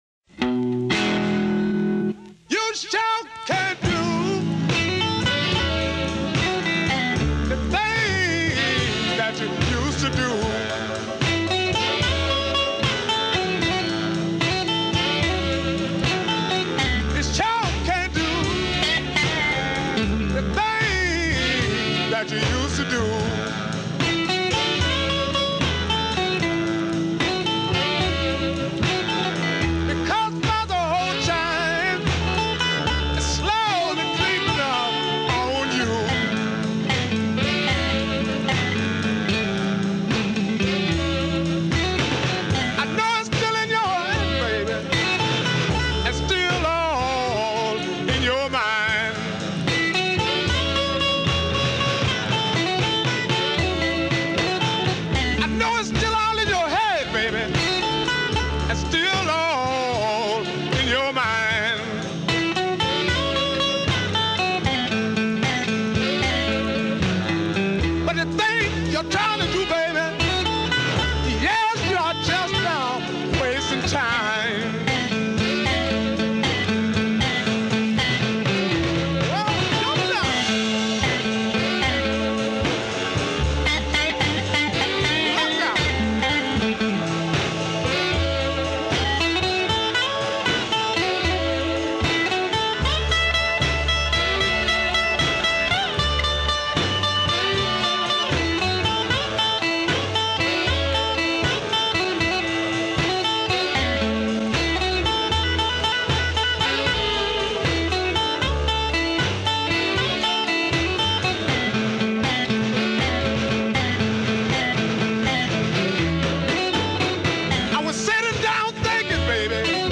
Guitar and Vocals
Bass
Drums
Piano
Tenor Sax
Baritone Sax